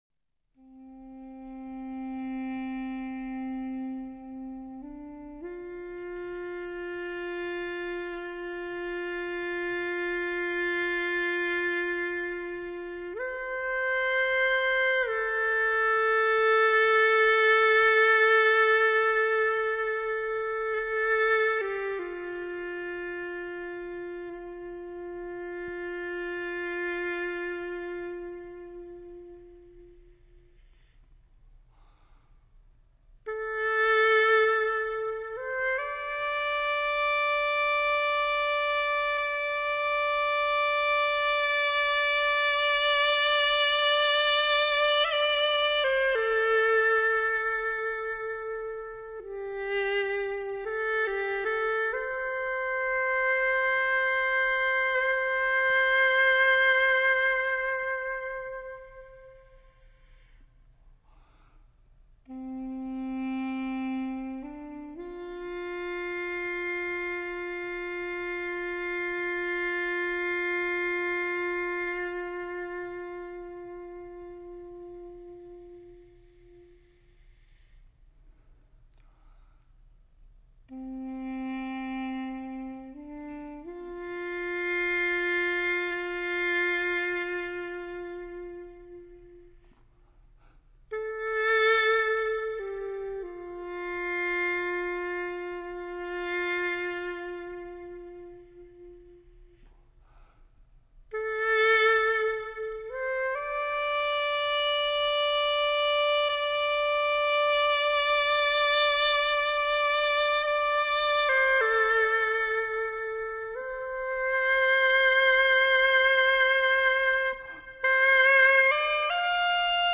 CD 08 管子独奏